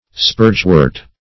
Search Result for " spurgewort" : The Collaborative International Dictionary of English v.0.48: Spurgewort \Spurge"wort`\ (sp[^u]rj"w[^u]rt`), n. (Bot.) Any euphorbiaceous plant.